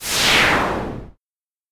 Undertale Swoosh Sound Effect Free Download
Undertale Swoosh